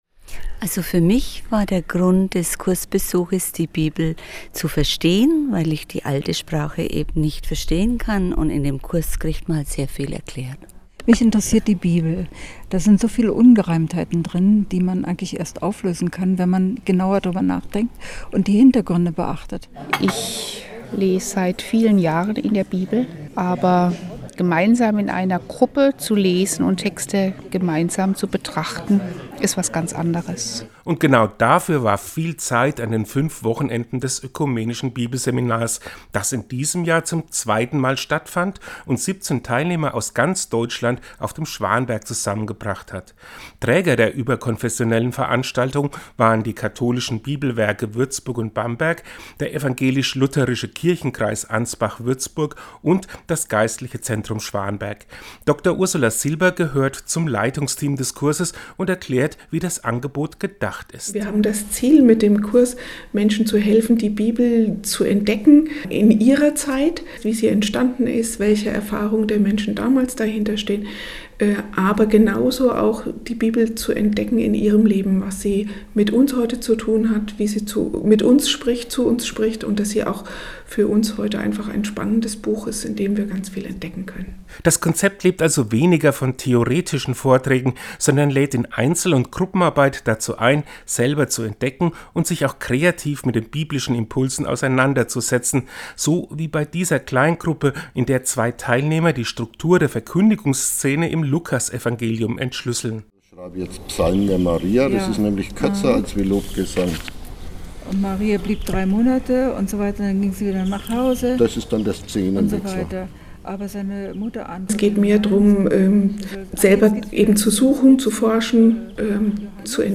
Seinen Radiobericht finden Sie unten als Download!